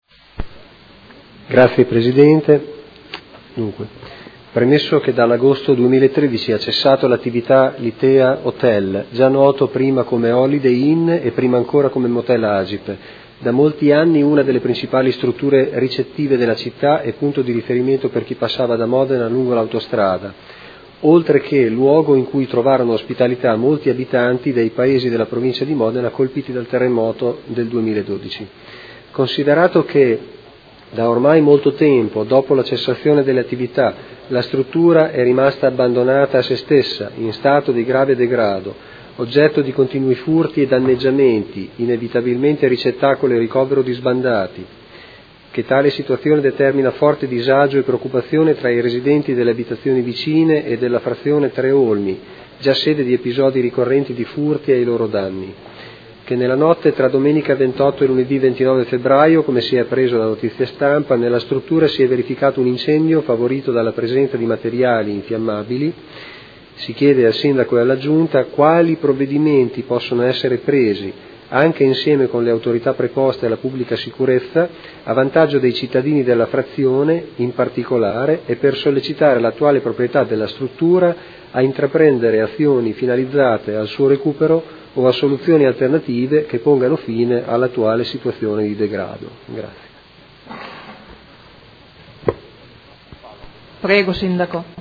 Seduta del 31/03/2016. Interrogazione dei Consiglieri Malferrari e Trande (P.D.) avente per oggetto: Degrado struttura ex albergo Holiday Inn